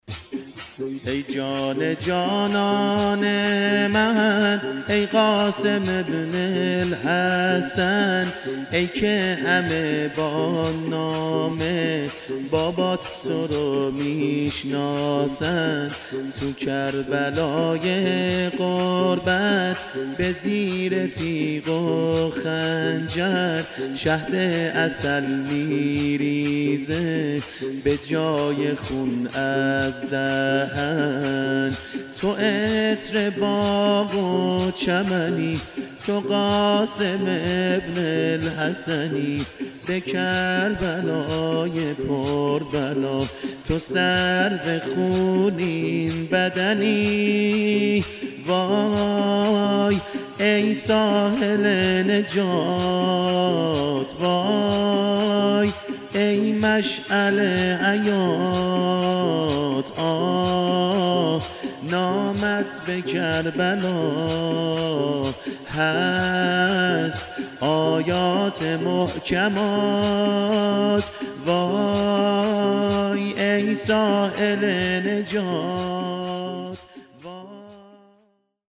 عنوان : زمینه حضرت قاسم بن الحسن(ع)